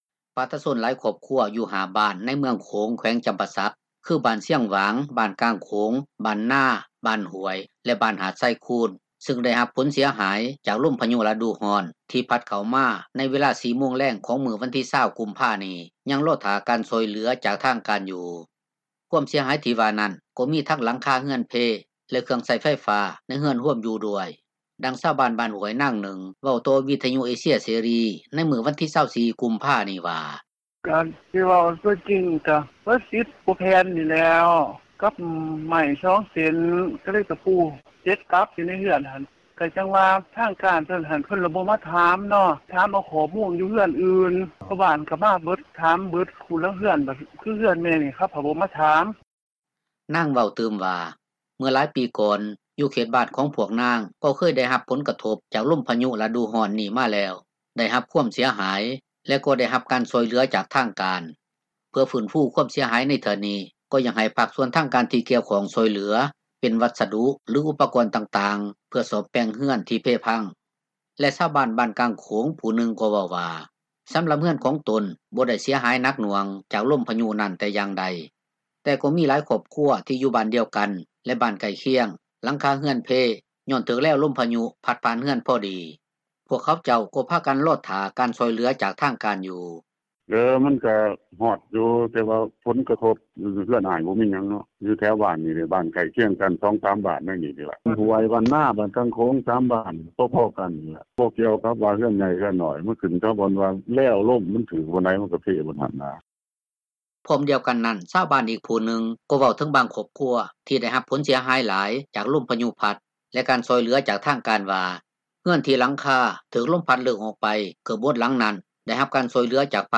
ດັ່ງຊາວບ້ານບ້ານຫ້ວຍນາງນຶ່ງເວົ້າຕໍ່ວິທຍຸເອເຊັຽເສຣີ ໃນມື້ວັນທີ 24 ກຸມພານີ້ວ່າ: